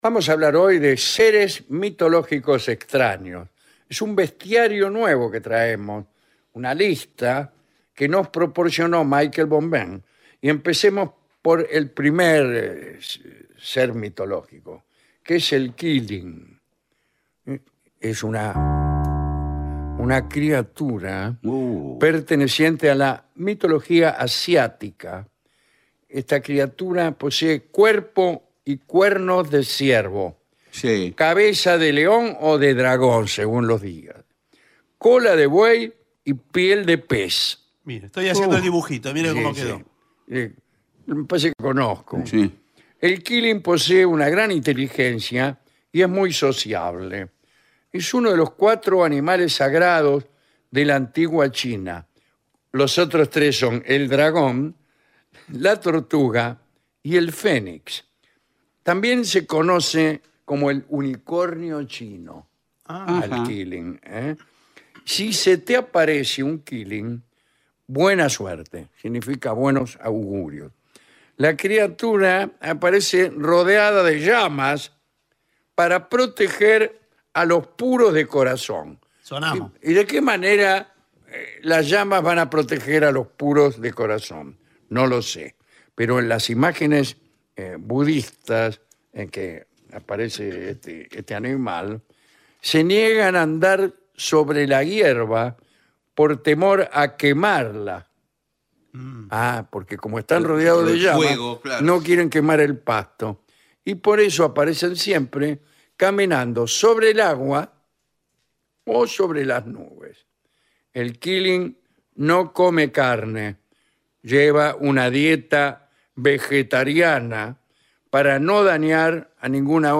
Un clásico de la radio rioplatense con la conducción de Alejandro Dolina